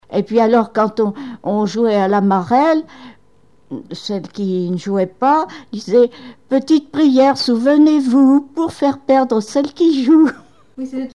Genre brève
Témoignages et chansons
Pièce musicale inédite